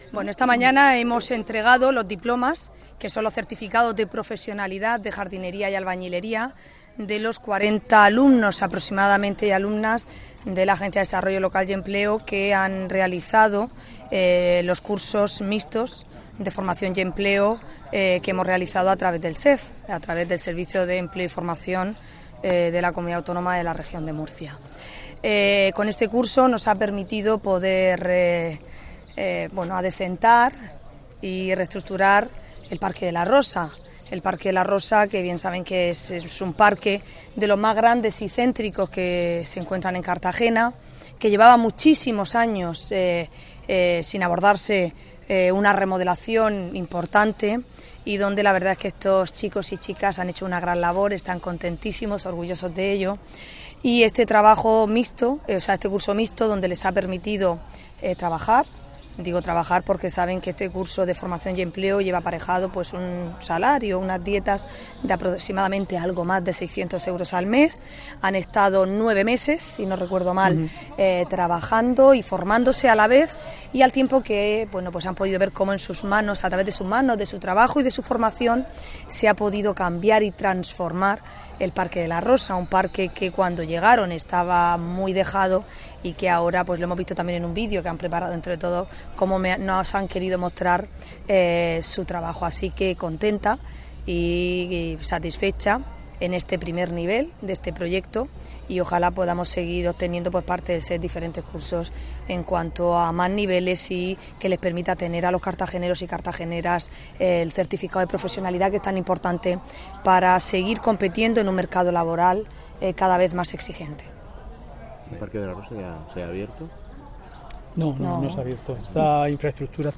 El Centro Ocupacional de Canteras ha acogido esta mañana el acto de clausura del programa ‘Operaciones auxiliares de acabados rígidos y urbanización’, un curso mixto de empleo y formación del Servicio Regional de Empleo y Formación (SEF) con el que colabora la Agencia de Desarrollo Local y Empleo (ADLE) y en el que han participado 40 personas.